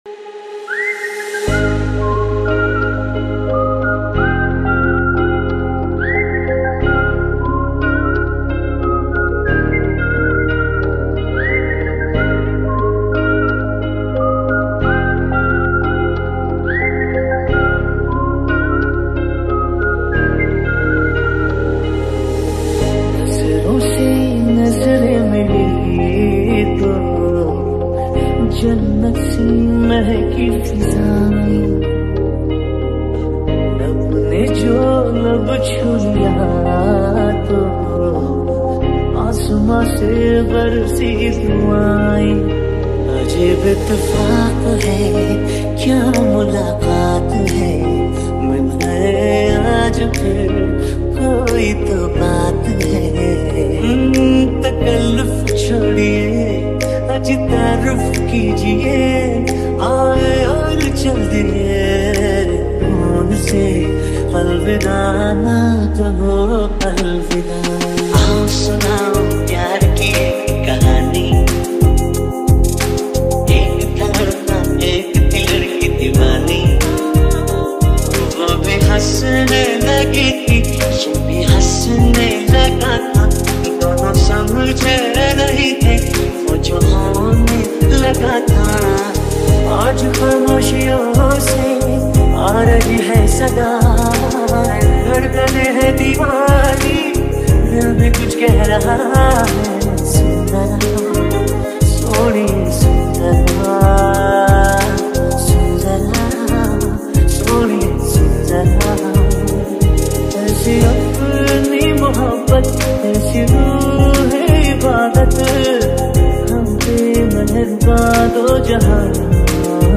High quality Sri Lankan remix MP3 (6.7).